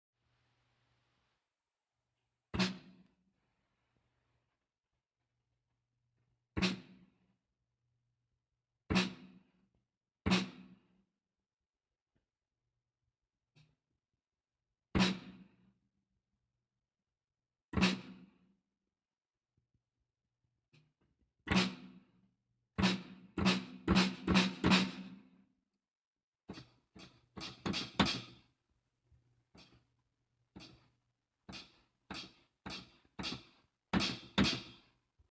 ну вот я везде поднял звук клавиатуры, даже в тихих местах.